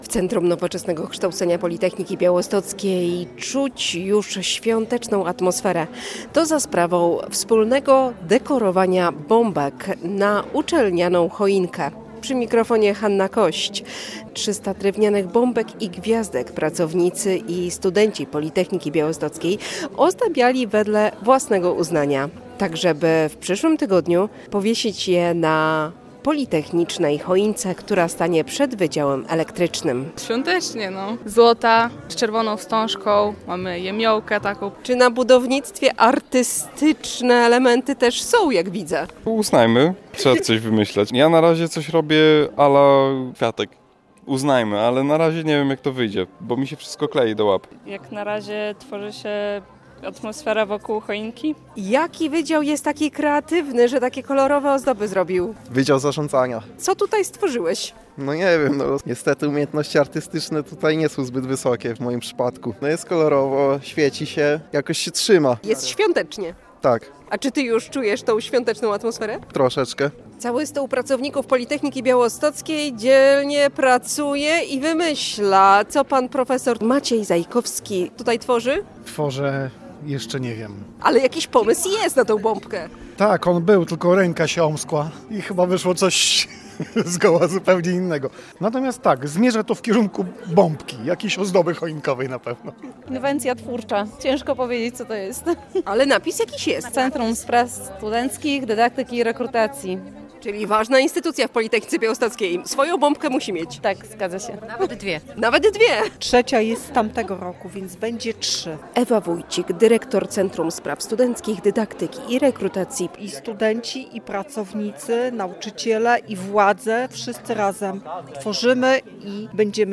– Jest świątecznie. Moja bombka jest złota, z czerwoną wstążką i jemiołą. Ja na razie coś robię à la kwiatek, ale na razie nie wiem, jak to wyjdzie, bo mi się wszystko klei do łap. Ręka mi się omskła i chyba wyszło coś z goła zupełnie innego niż chciałem, ale zmierza to w kierunku jakiejś ozdoby choinkowej – mówią studenci i wykładowcy Politechniki Białostockiej.